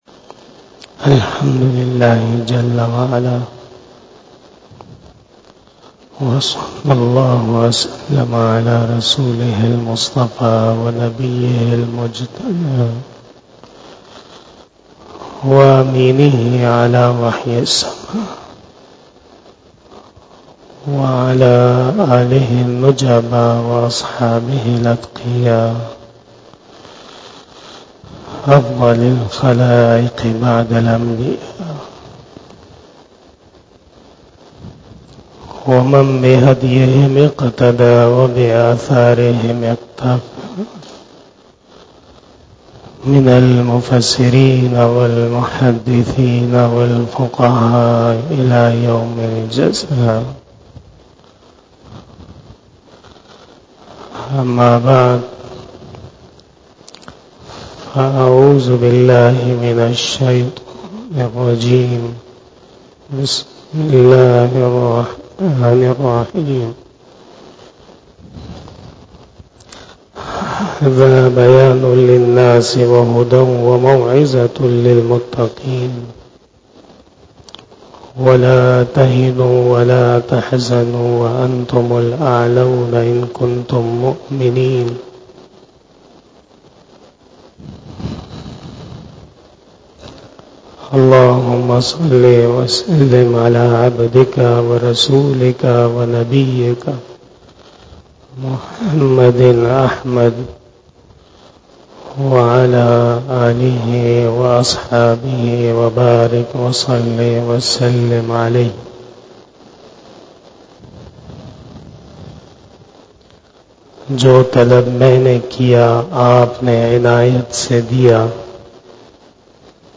35 BAYAN E JUMMAH 01 Sept 2023 ( 14 Safar 1445HJ)
Khitab-e-Jummah 2023